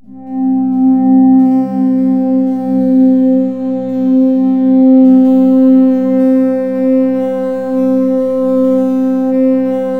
WIRE PAD2.wav